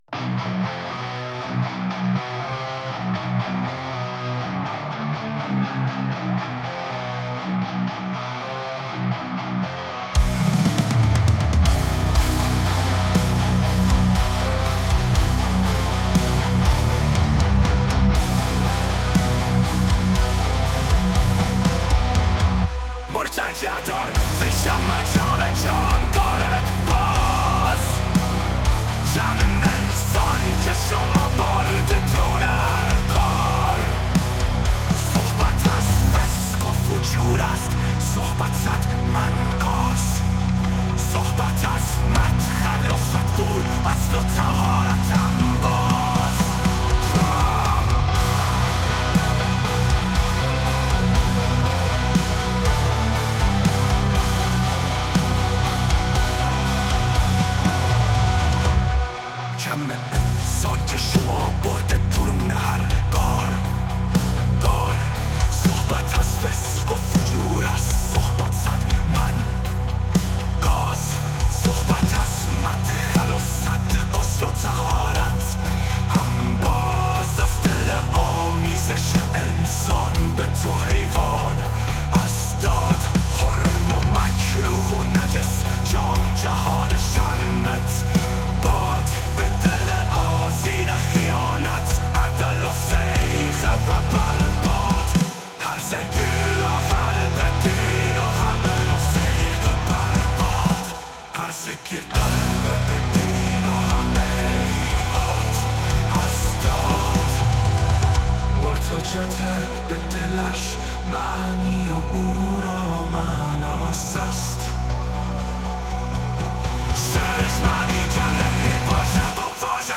تمامی موسیقی‌های موجود در وب‌سایت جهان آرمانی توسط هوش مصنوعی ساخته شده است، هوش مصنوعی که قادر به ساخت موسیقی با توجه به شعر و سبک مورد نظر است
ساخت موسیقی در سبک‌های راک، متال و زیر شاخه‌های آن انجام شده، دلیل انتخاب این سبک‌ها قرابت این موسیقی‌ها با اشعار پیچیده است